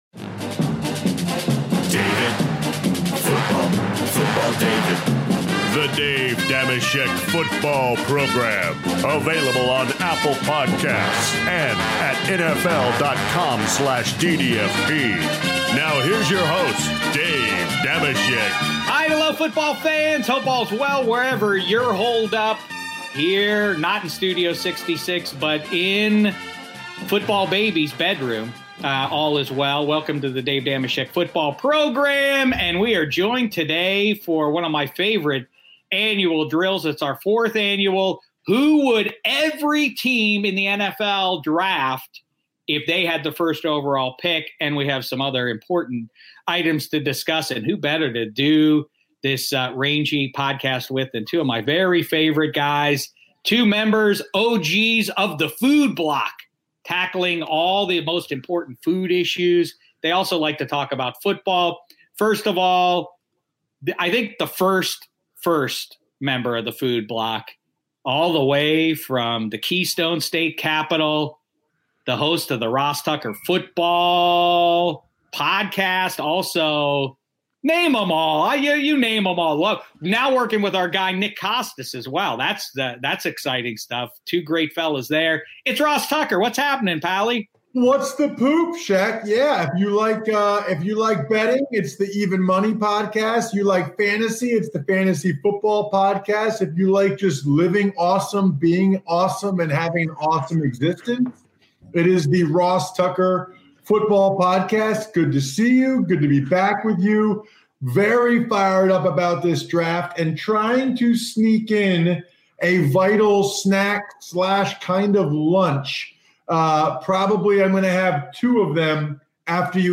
via video chat